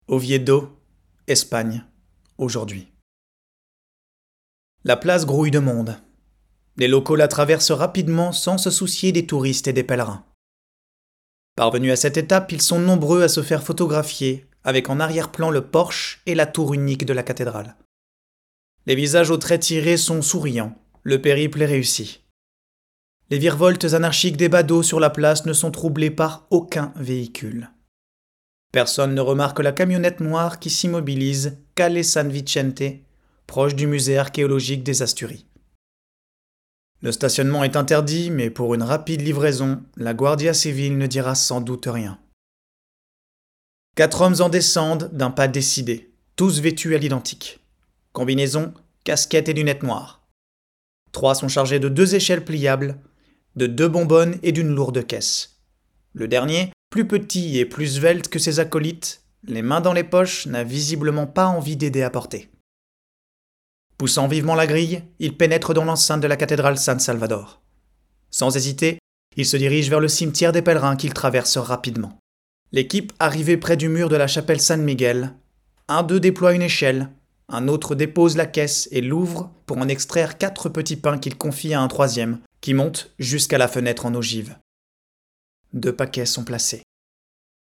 Voix off
Court extrait parlé, quotidien
10 - 35 ans - Contre-ténor